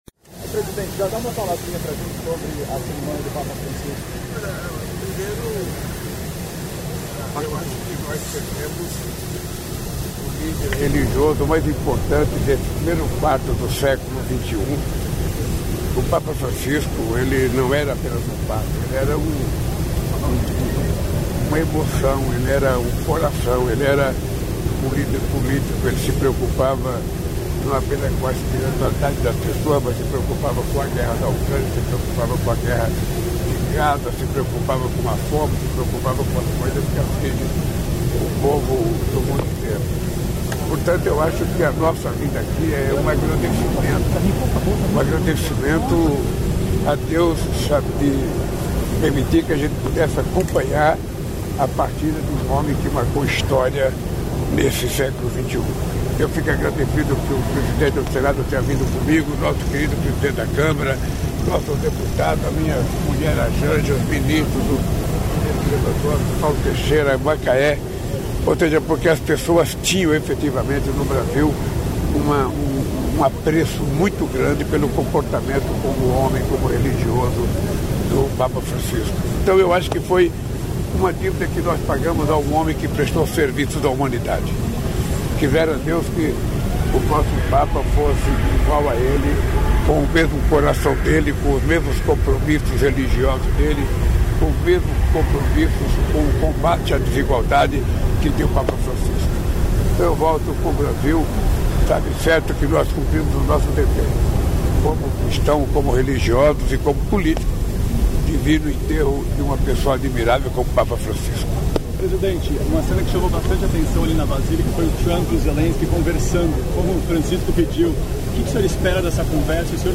Presidente Lula conversa com jornalistas após participar de funeral do Papa Francisco, neste sábado (26), em Roma, na Itália.